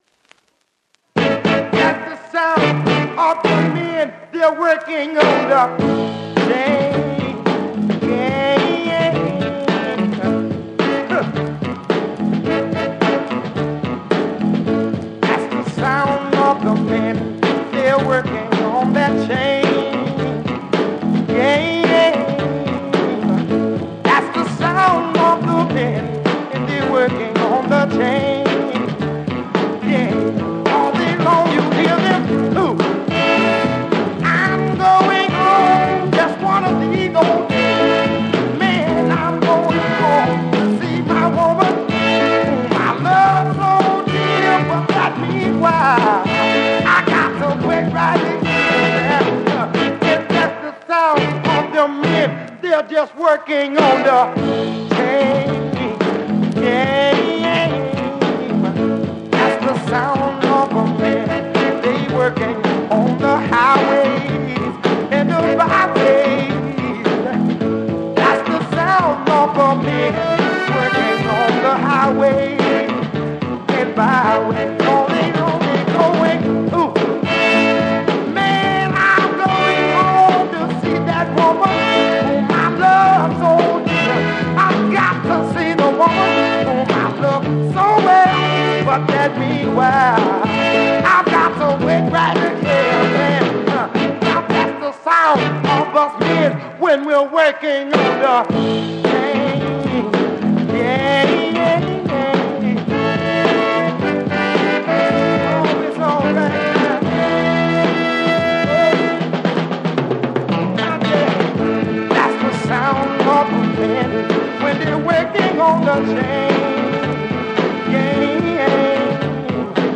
Classic Soul funk out as the French picture sleeve.